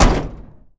carKick1.ogg